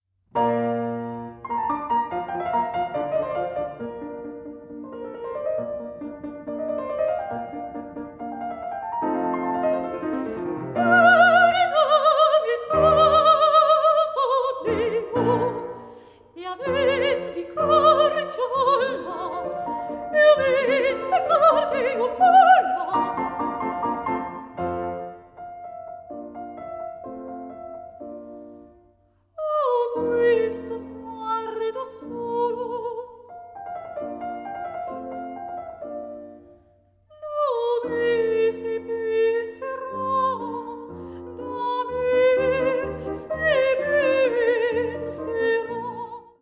VOCAL MUSIC
PIANO MUSIC